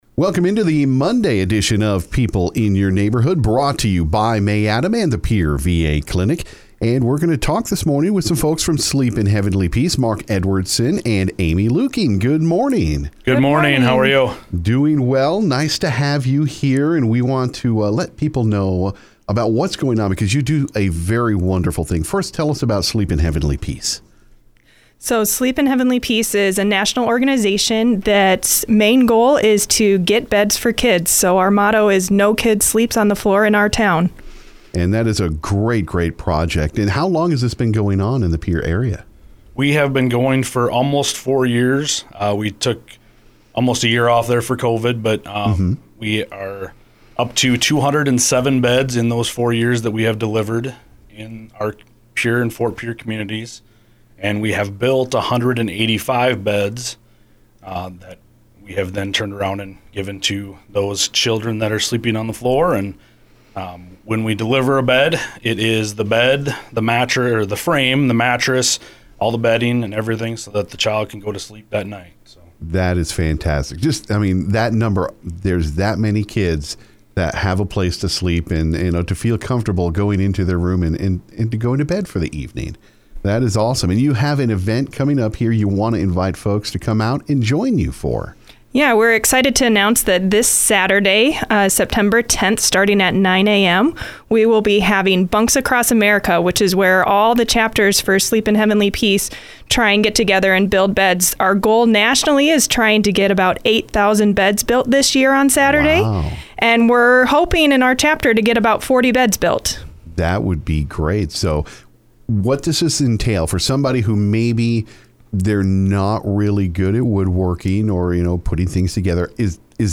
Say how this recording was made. visited the KGFX studio